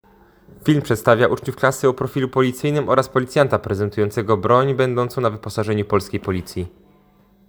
Opis nagrania: Audiodeskrypcja